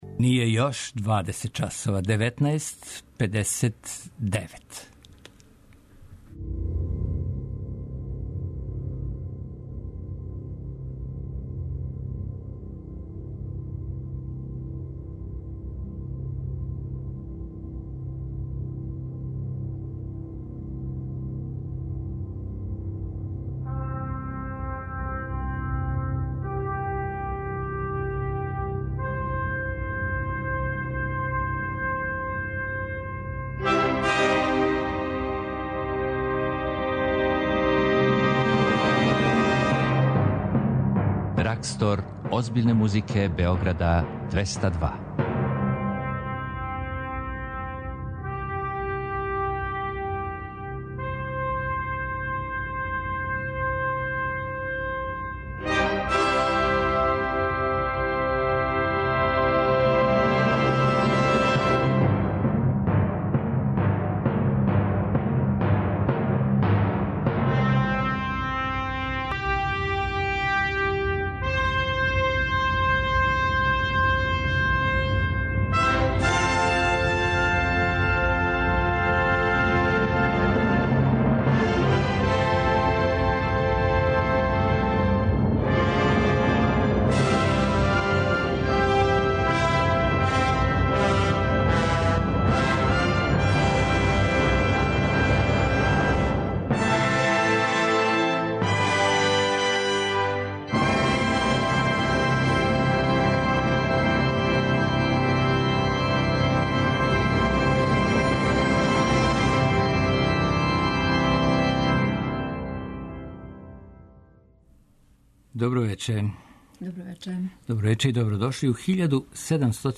Овог понедељка то ће бити наступ студената камерне музике Факултета музичке уметности!